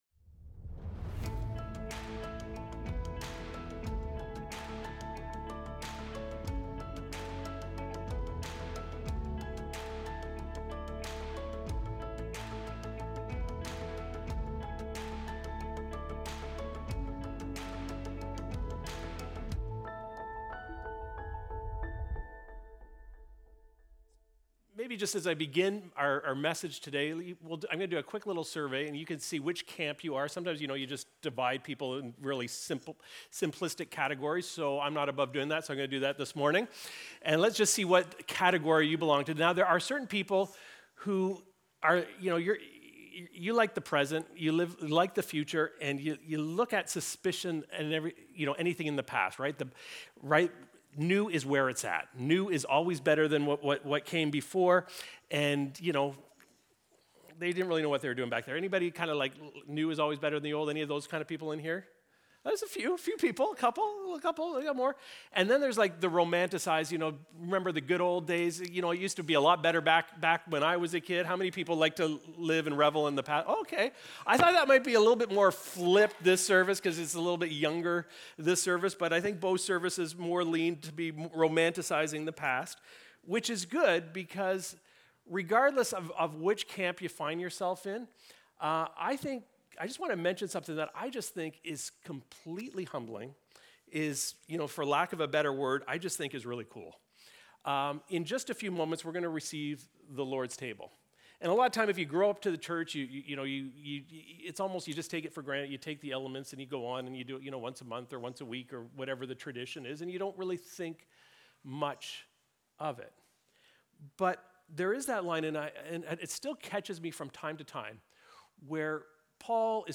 Recorded Sunday, November 2, 2025, at Trentside Bobcaygeon.